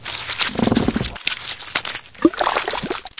barnacle_die2.ogg